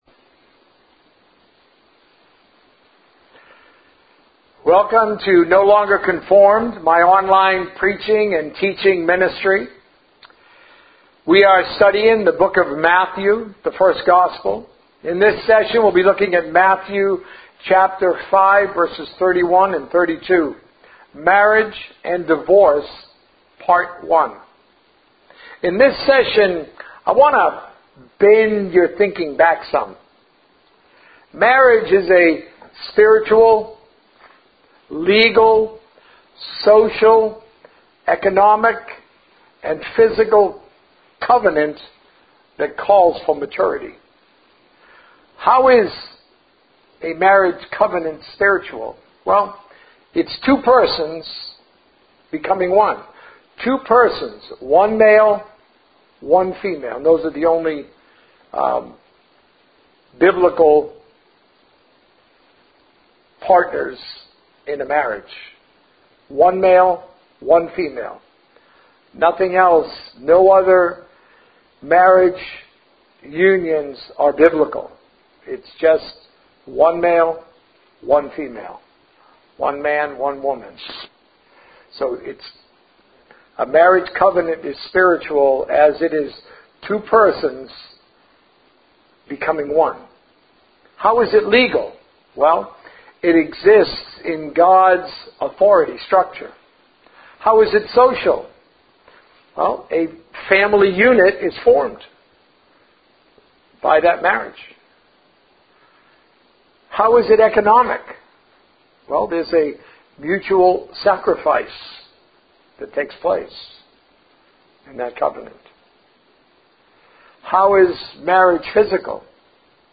A message from the series "The First Gospel." Marriage and Divorce - Part 1